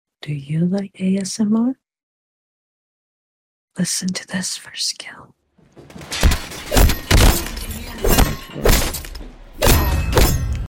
Just A Little Razor ASMR. sound effects free download